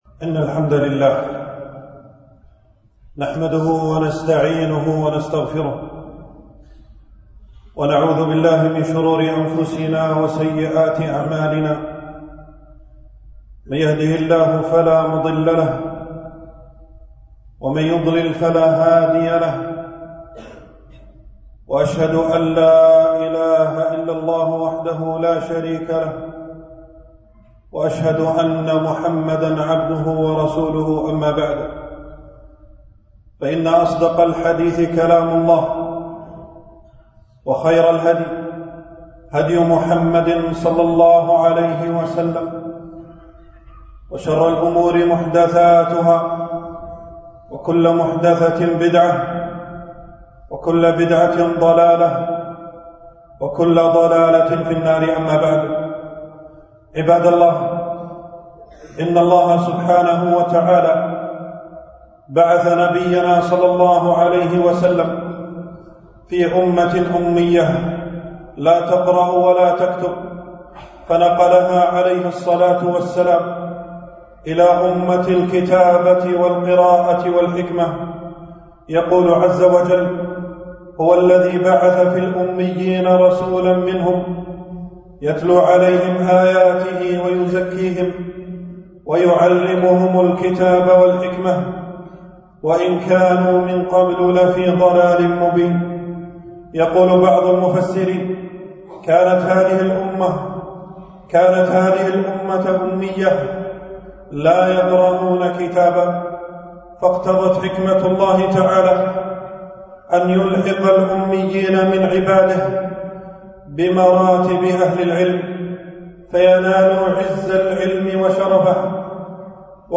خطبة الجمعة: القراءة مفتاح العلوم